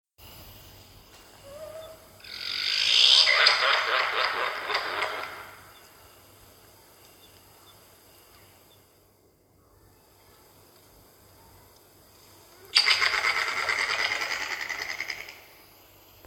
Audio recorder array surveys 2023 – In partnership with Mid Coast Council and the Department of Primary Industries we will set up an array of 25-30 audio recorders to capture the dulcet sound of male koala breeding bellows and yellow-bellied glider mating calls (both attached below).